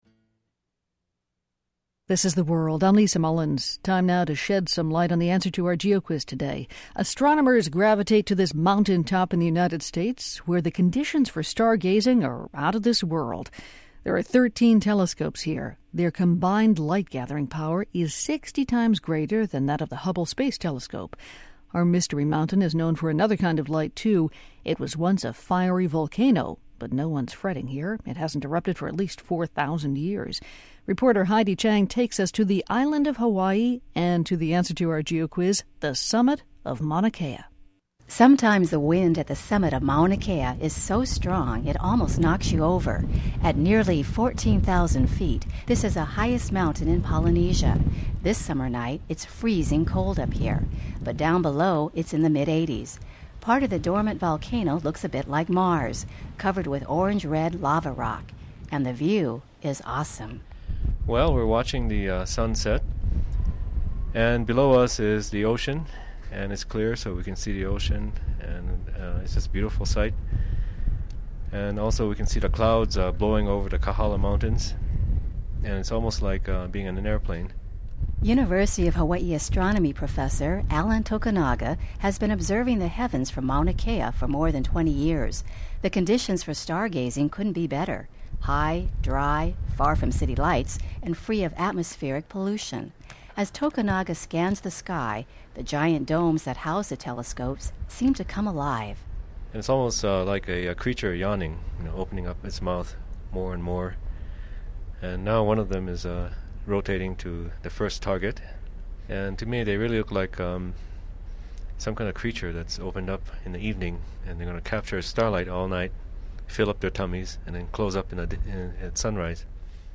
Aired on PRI’s The World on August 18, 2005
Interviews: